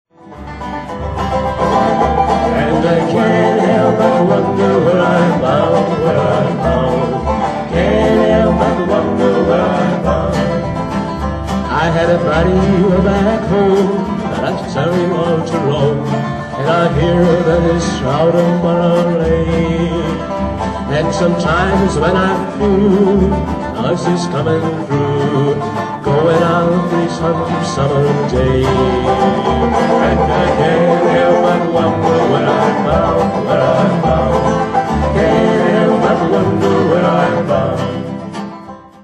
■２００３年９月２1日（火）練習■新所沢パルコ
新課題曲練習